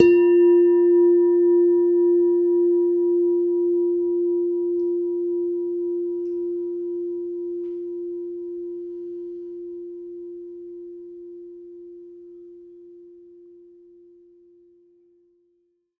mono_bell_-9_F_16sec
bell bells bell-set bell-tone bong ding dong ping sound effect free sound royalty free Sound Effects